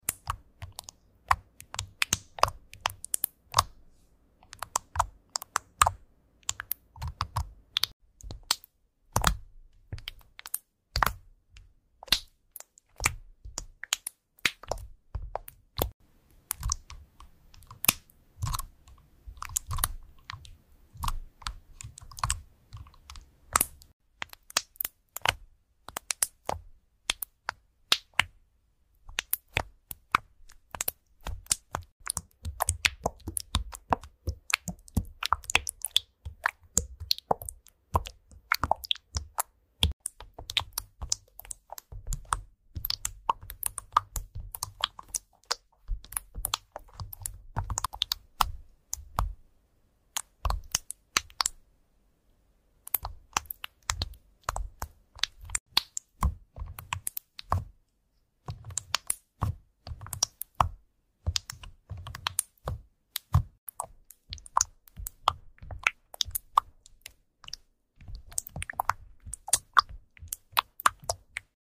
Typing this special keyboards ASMR sound effects free download